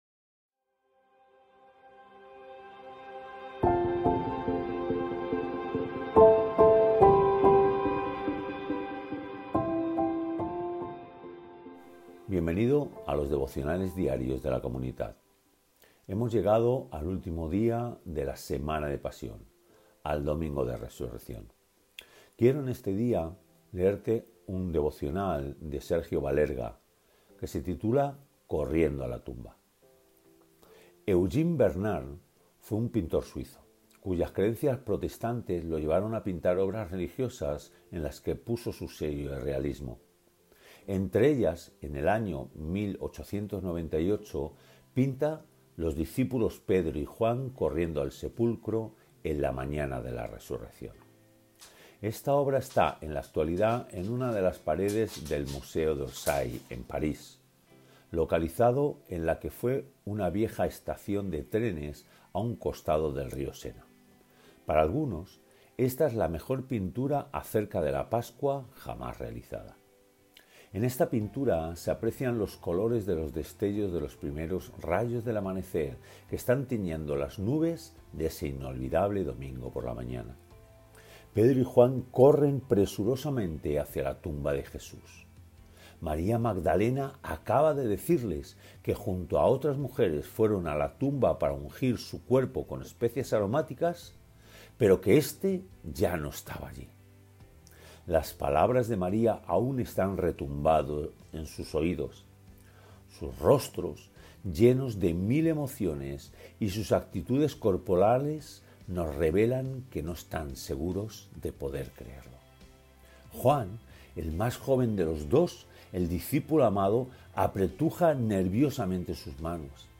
Narrado por el Pastor